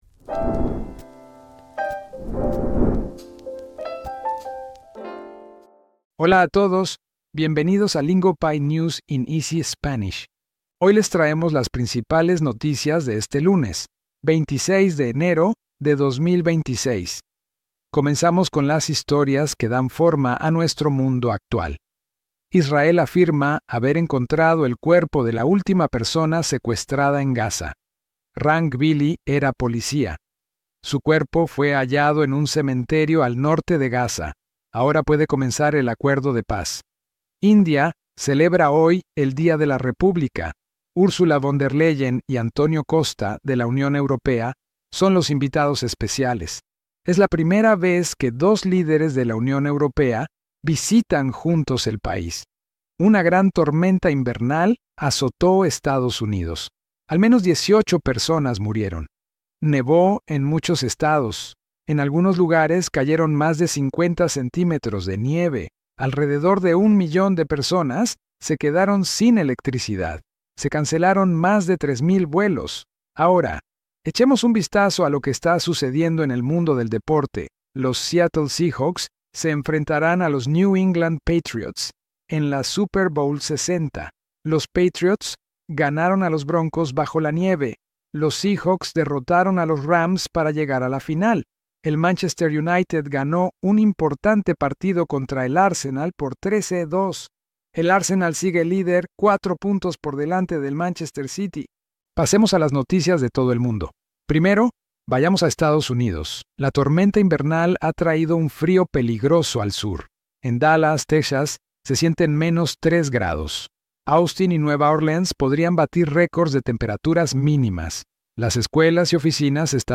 Lingopie’s News in Easy Spanish brings you real headlines from around the world, told in clear and steady Spanish, built for learners.